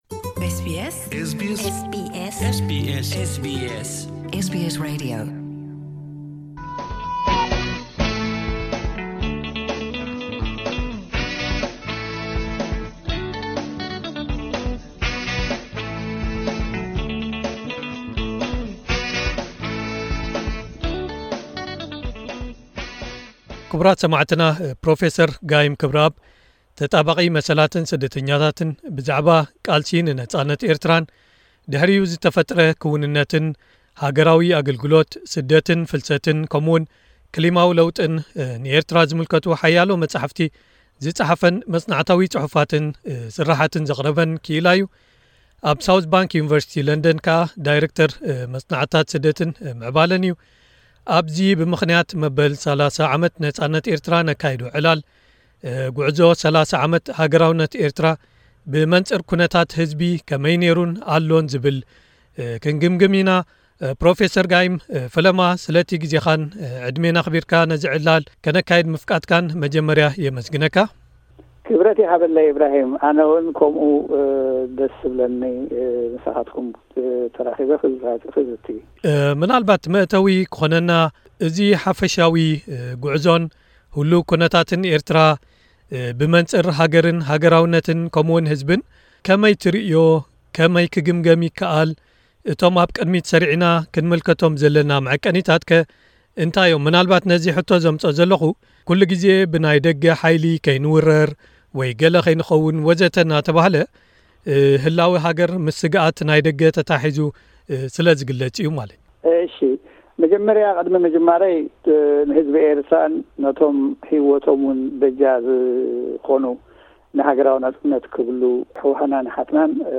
ቃለመሕትት